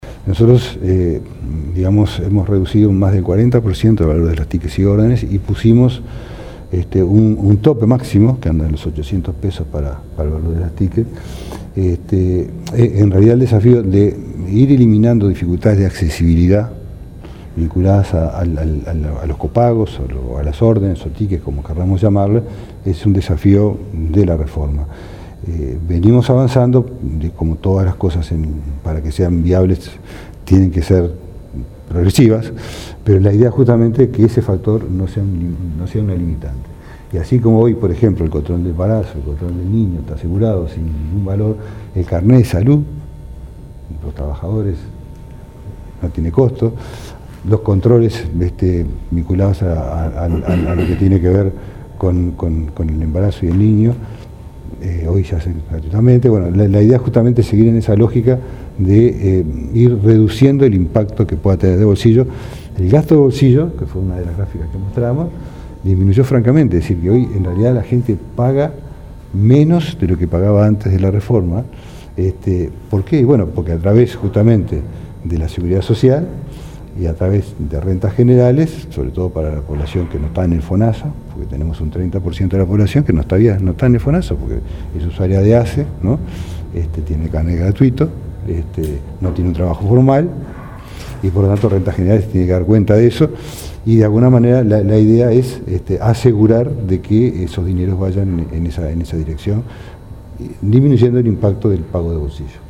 “Redujimos a más del 40 % el valor de tickets y órdenes y pusimos un tope máximo de $ 800 para el valor del ticket”, informó el ministro de Salud Pública, Jorge Basso, en el acto por los 10 años del SNIS. El desafío es eliminar dificultades de accesibilidad vinculadas a estos servicios para que no sea una limitante en la atención.